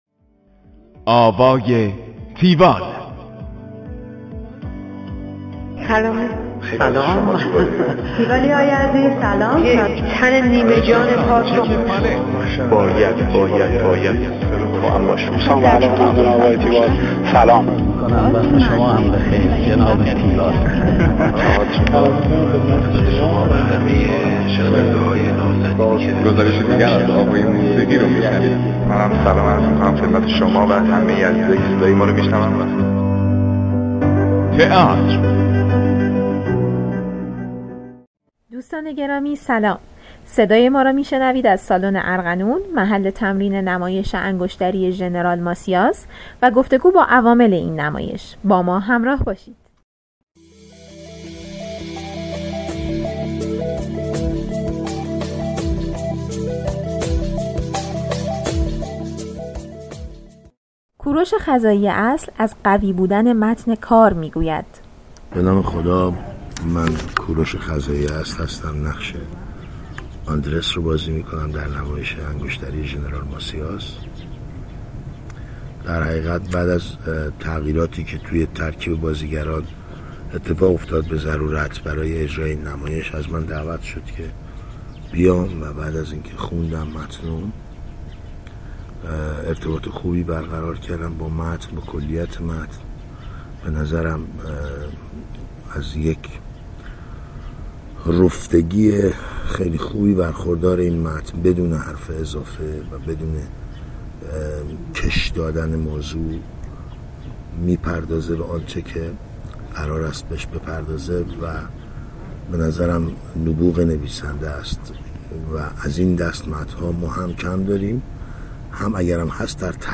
گزارش آوای تیوال از نمایش انگشتری ژنرال ماسیاس